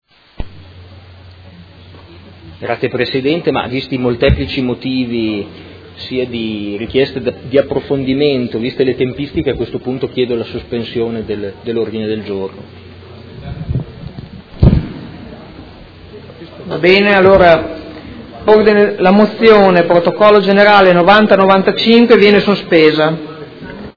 Seduta del 5/04/2018. Chiede sospensione della mozione presentata dai Consiglieri Forghieri, Liotti, Poggi, De Lillo, Venturelli, Carpentieri e Bortolamasi (PD) avente per oggetto: Sostegno al percorso di autonomia istituzionale intrapreso dalla Regione